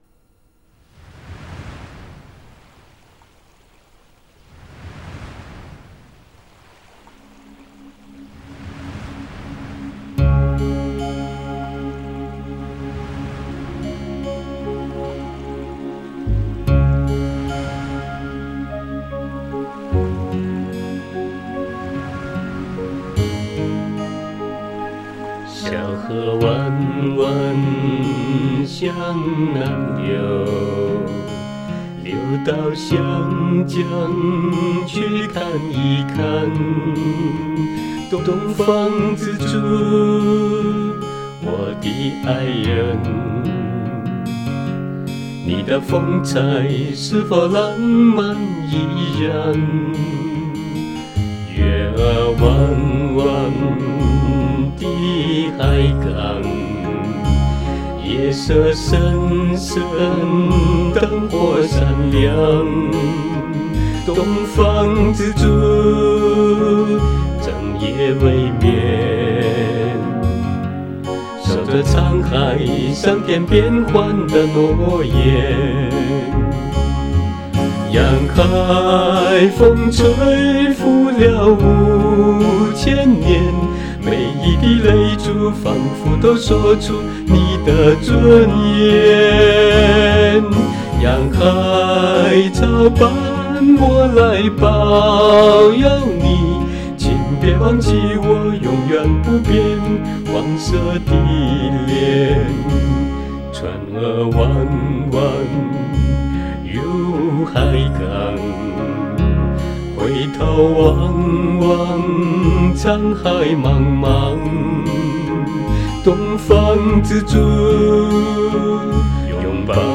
只好唱首歌，祝愿香港平安。
久违了未知深情优美的歌声！
通过你这港味十足的优美歌声祝福香港！